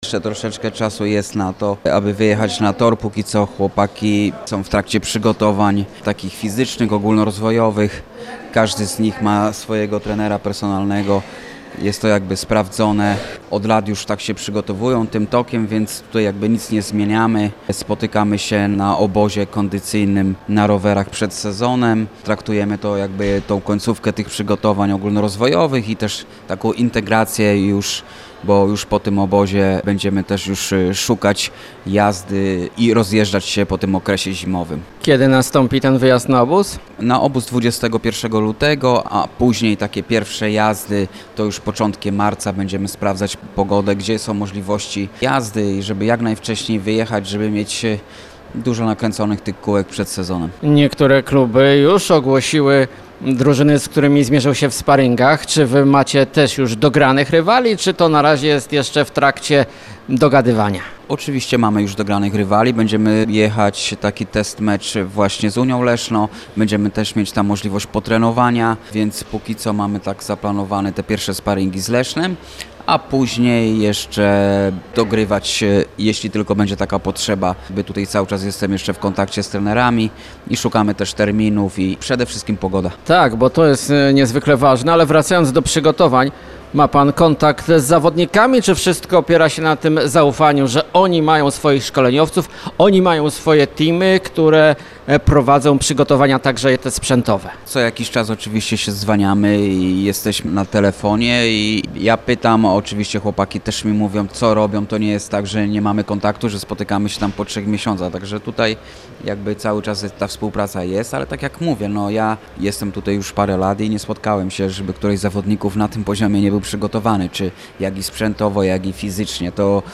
Cała rozmowa w materiale dźwiękowym: